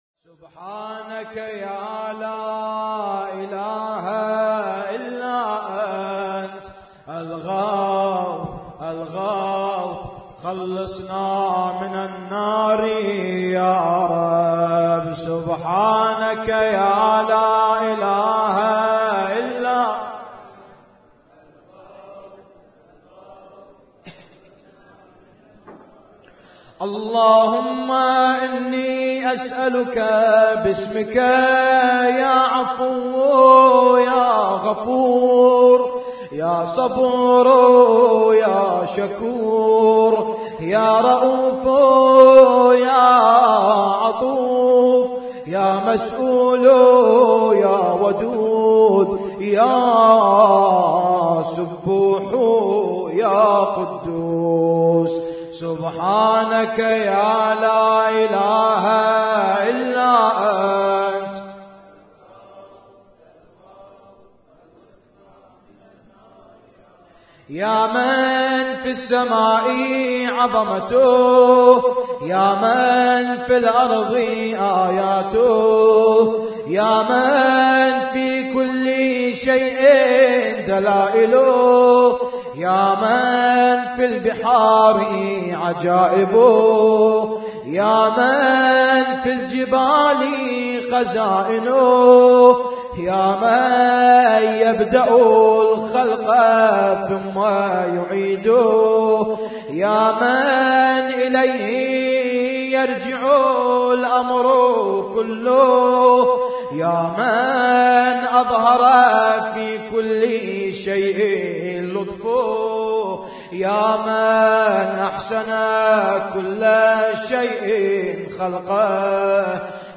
فقرات من دعاء الجوشن الكبير - احياء ليلة 22 رمضان 1436
اسم التصنيف: المـكتبة الصــوتيه >> الادعية >> ادعية ليالي القدر